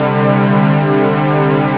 sus 2 synth strings.wav